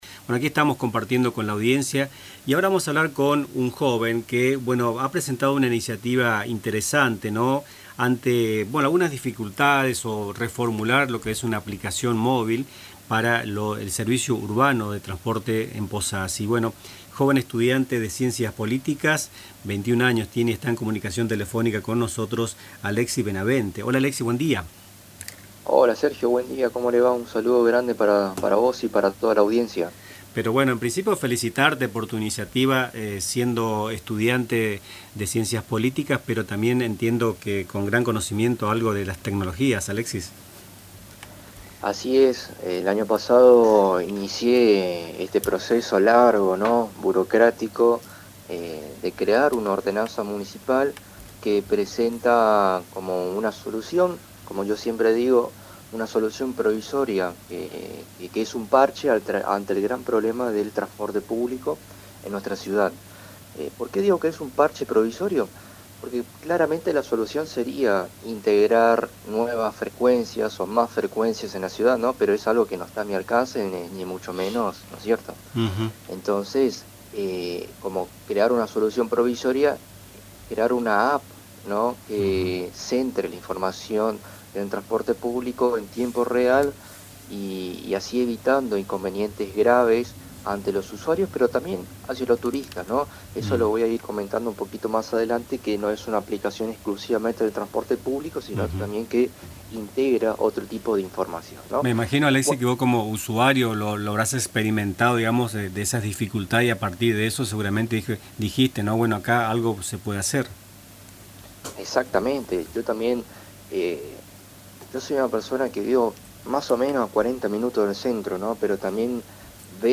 En “Nuestras Mañanas”, entrevistamos